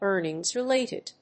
アクセントéarnings‐reláted